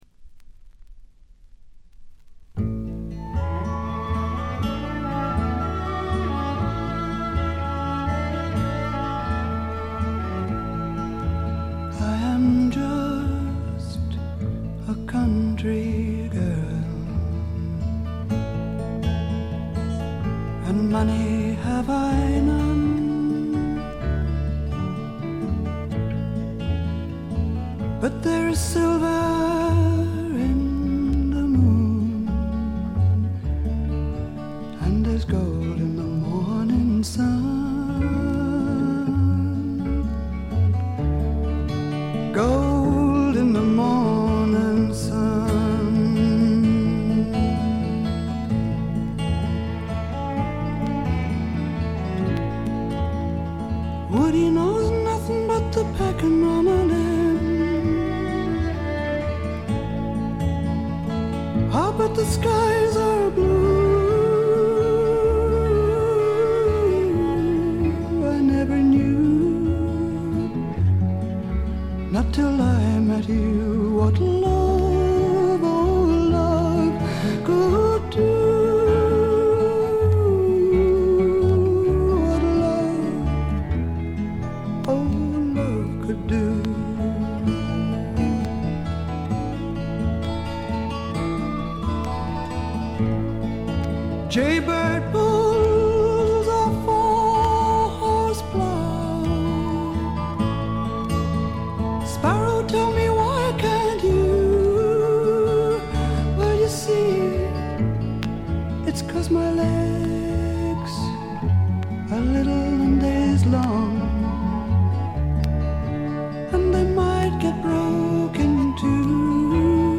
B2序盤から中盤にかけて間欠的に周回ノイズ。
実に魅力的なアルト・ヴォイスの持ち主で、初めて聴く方はまずはこの声にやられてしまうことでしょう。
この強力な声を武器にシンプルなバックを従えて、フォーキーでジャジーでアシッドでダークなフォークロックを展開しています。
試聴曲は現品からの取り込み音源です。
Vocals ?
Guitar ?
Bass ?